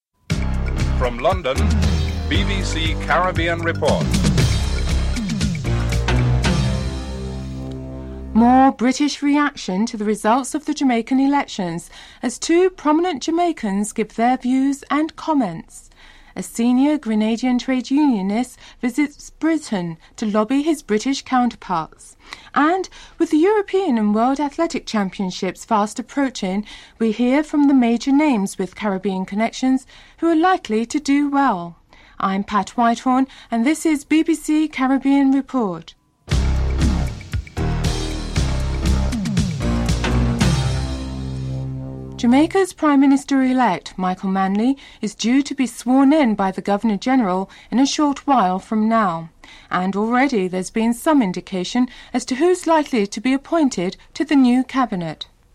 4. Financial News (07:50-09:37)
6. Interviews with Jamaicans, Merlene Ottey and Grace Jackson on the forth-coming European Indoor World Championship in Budapest (12:39-15:00)